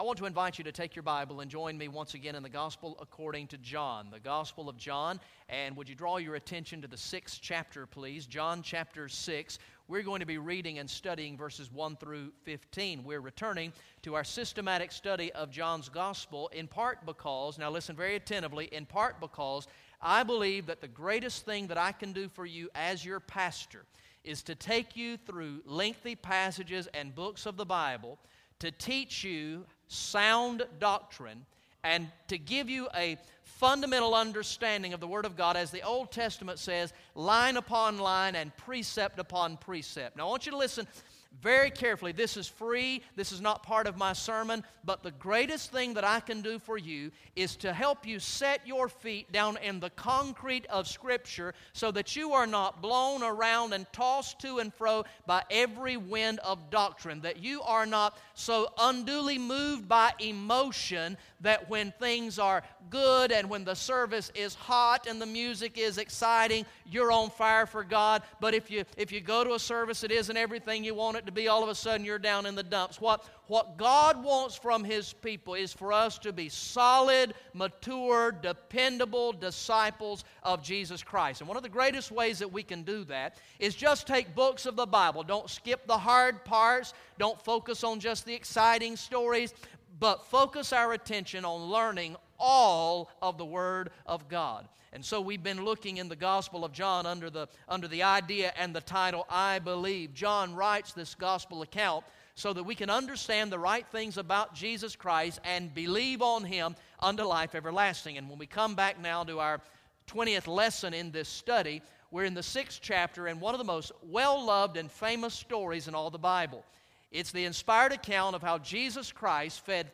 Message #20 from the sermon series through the gospel of John entitled "I Believe" Recorded in the morning worship service on Sunday, September 28, 2014